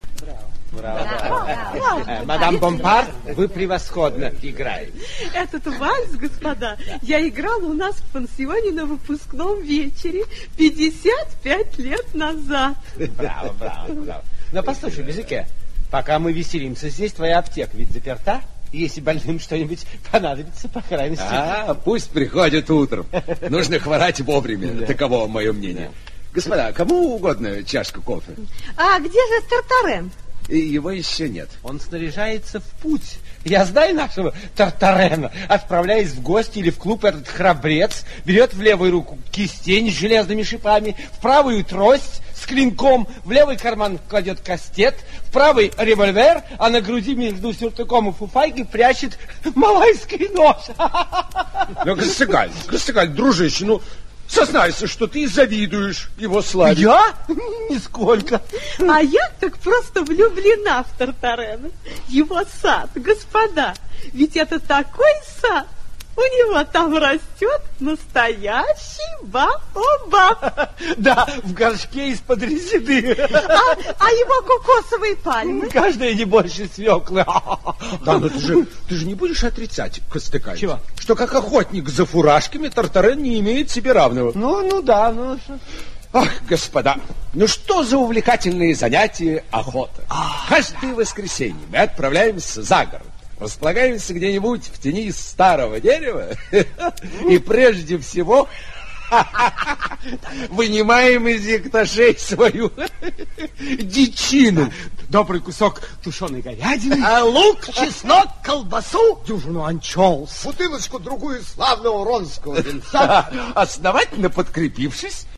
Аудиокнига Тартарен из Тараскона (спектакль) | Библиотека аудиокниг
Aудиокнига Тартарен из Тараскона (спектакль) Автор Альфонс Доде Читает аудиокнигу Рубен Симонов.